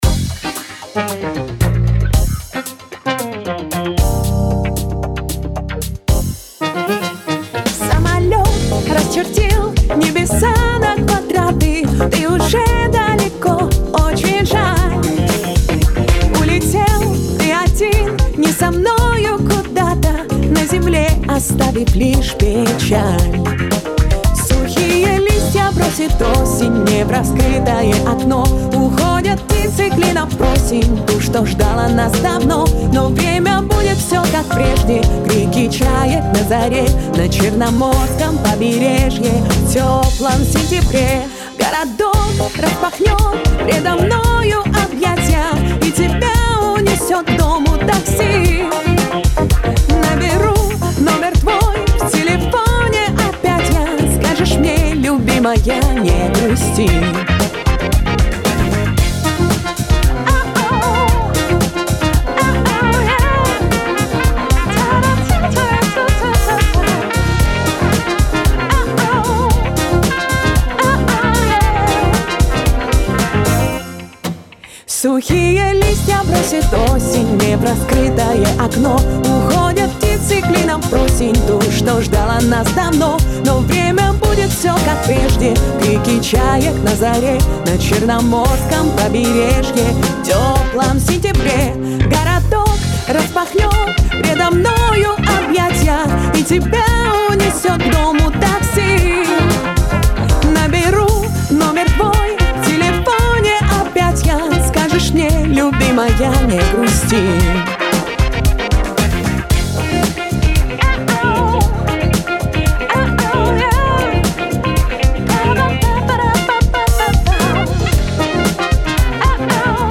В тёплом сентябре ( где-то рядом с funk)
Cделал все таки поярче. Поплыл голос (и не только) Нет,мне как-то не очень.